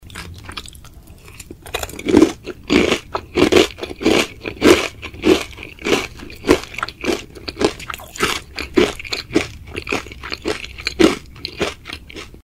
Звуки поедания еды
Жевание и хруст пищи